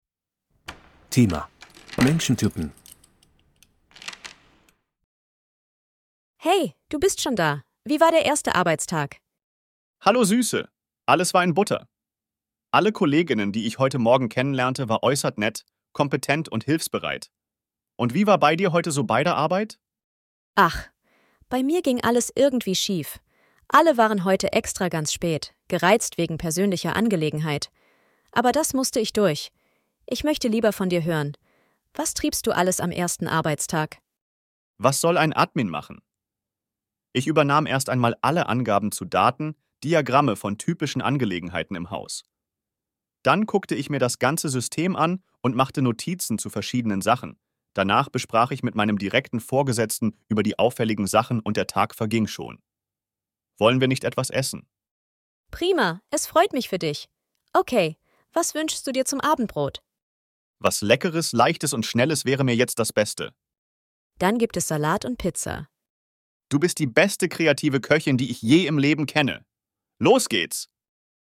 Hörtext für die Dialoge bei Aufgabe 4: